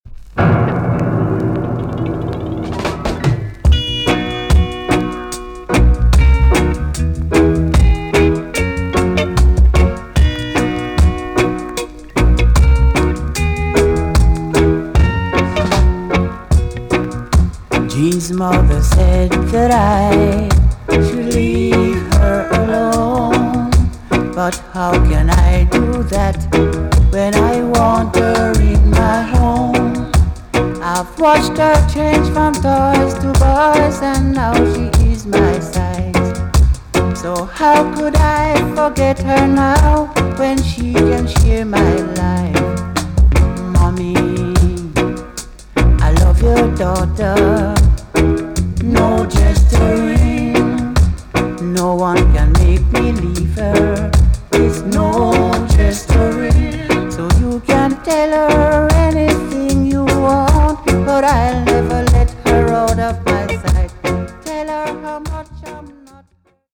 TOP >REGGAE & ROOTS
EX- 音はキレイです。
1975 , NICE VOCAL TUNE!!